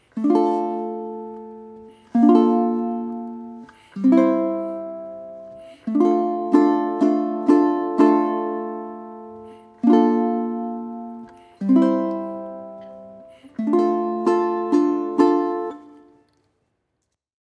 thumb strum icon Simple Strum 4/4
On the audio track, simple strum 4/4 is used: one strum per measure on beat one; that is, strum every four beats.
First_and_Only_strum.mp3